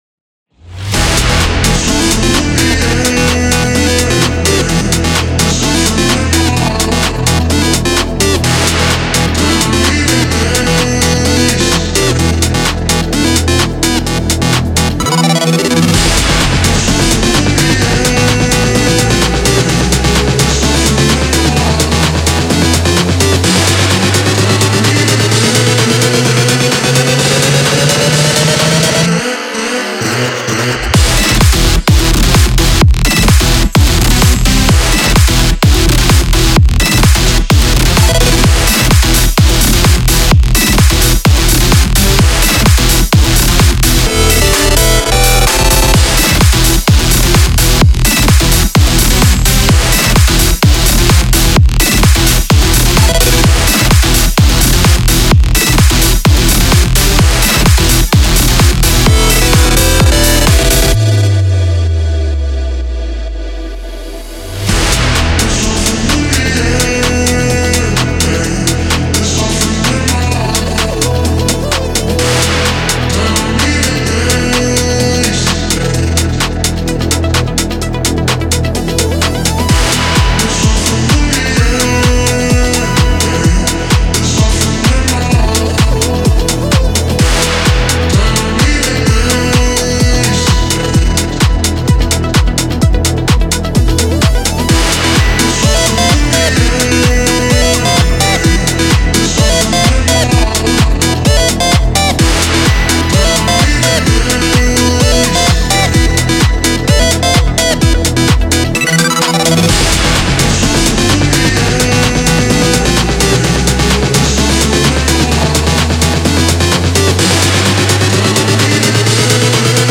EDM(ダンスミュージック)というジャンルの曲なのですが、YouTubeやこのブログでも紹介しています。